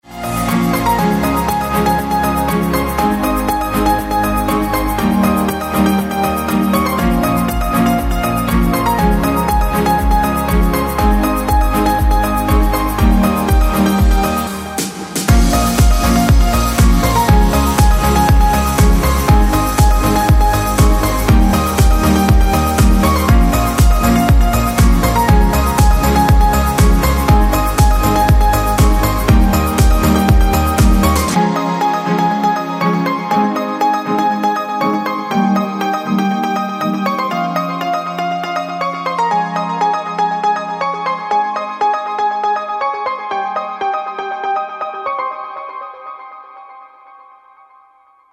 • Качество: 128, Stereo
ритмичные
без слов
колокольчики
яркие
Красивый проигрыш из песни